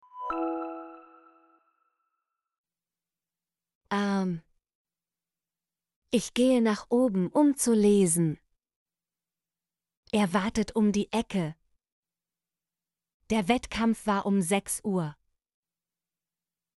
um - Example Sentences & Pronunciation, German Frequency List